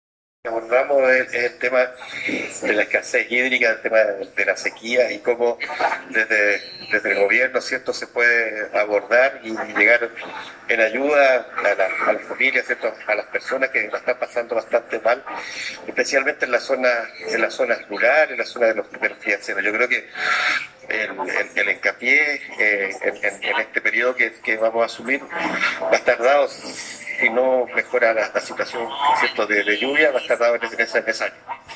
CORE-ELECTO-PEDRO-VALENCIA-ELQUI.mp3